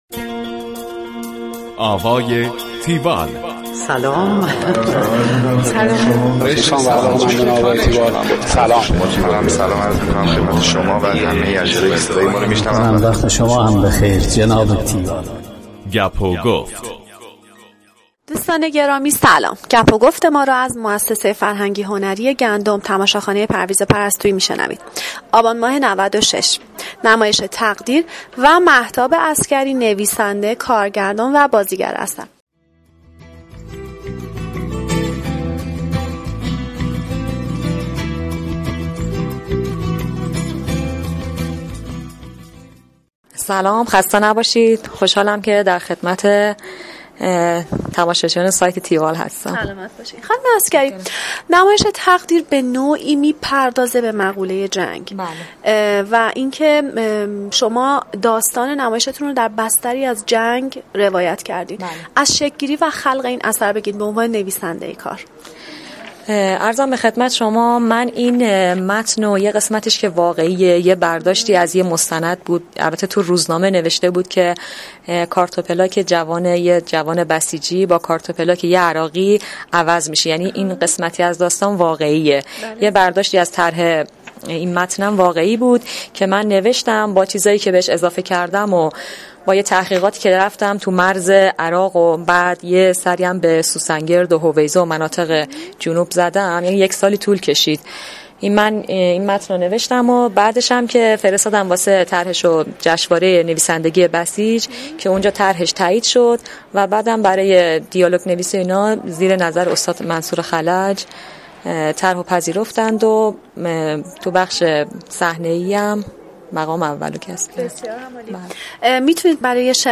دانلود فایل صوتی گفتگوی تیوال